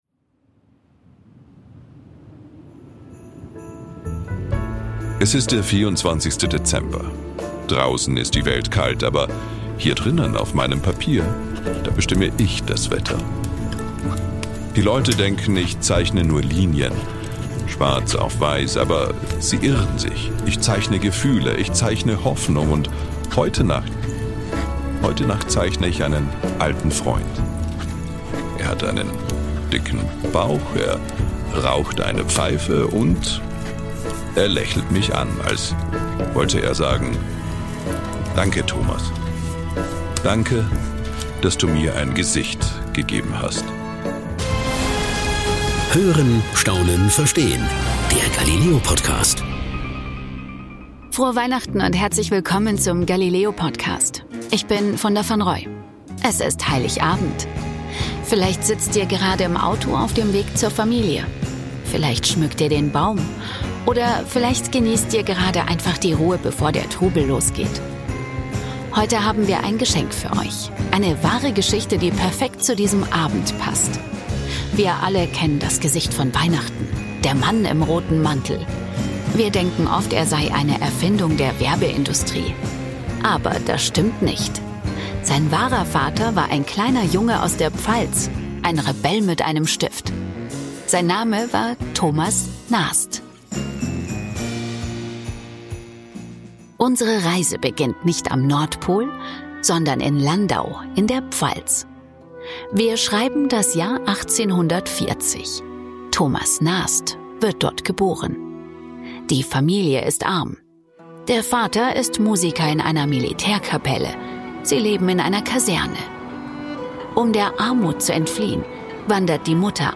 In dieser besonderen Weihnachtsfolge erzählt Funda Vanroy die unglaubliche Lebensgeschichte von Thomas Nast.